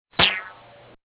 Rubber band.mp3